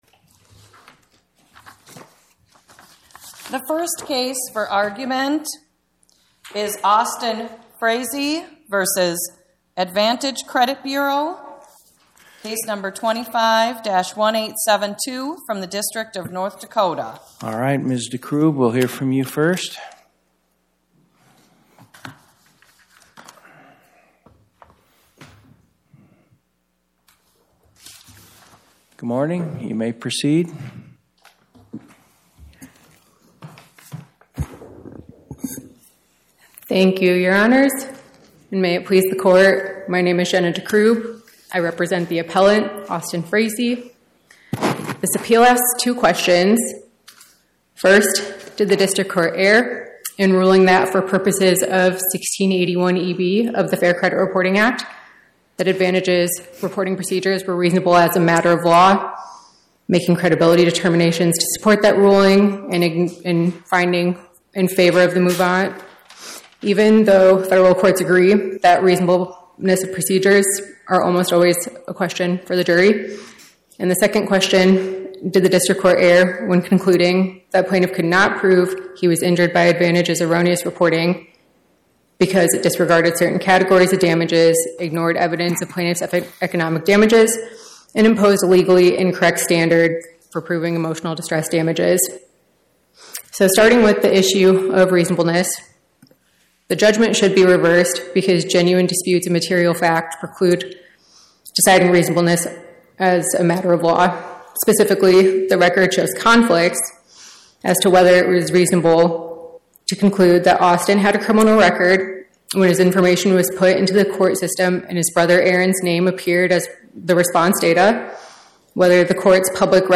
Oral argument argued before the Eighth Circuit U.S. Court of Appeals on or about 03/17/2026